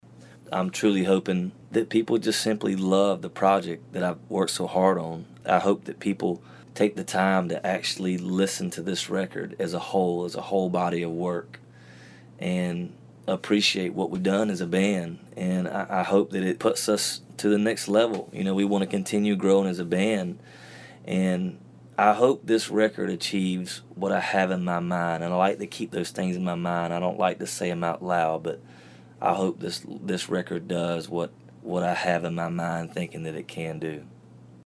Audio / Kip Moore talks about his hopes for the new album, Wild Ones.